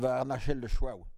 Localisation Saint-Christophe-du-Ligneron
Langue Maraîchin
Catégorie Locution